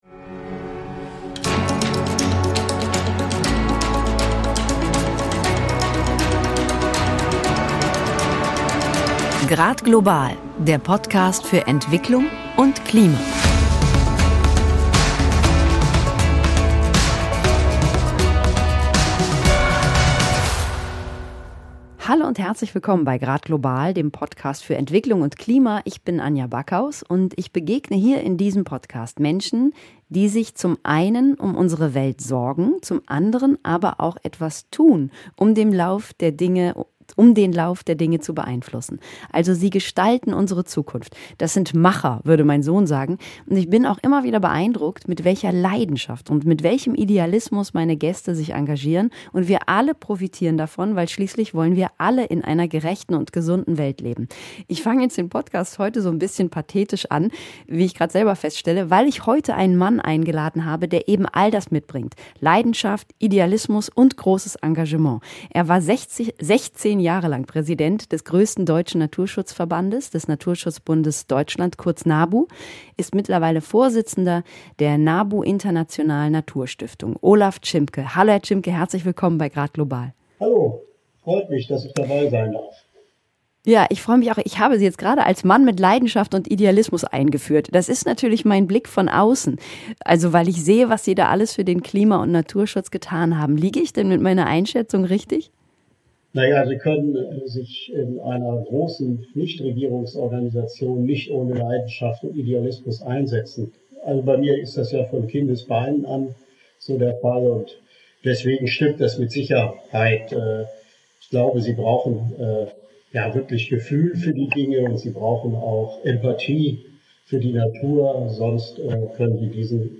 Im Gespräch erläutert er, warum sich beides bedingt. Lösungsansätze, die beide Themen gemeinsam angehen, sieht er in der Wirtschaft.